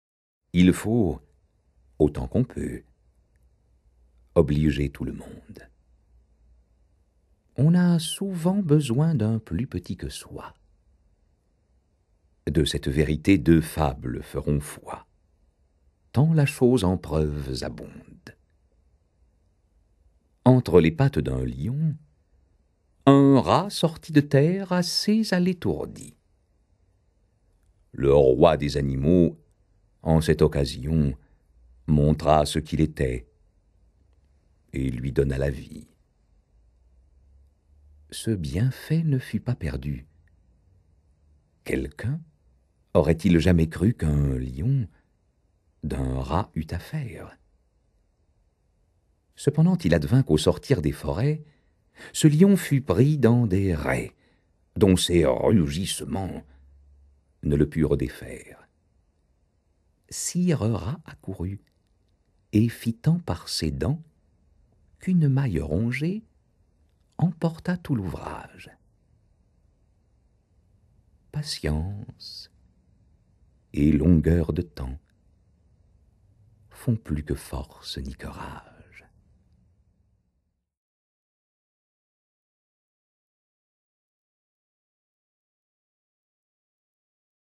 Click for an excerpt - Le Chant des oiseaux de Jean de La Fontaine
la voix classique et chaude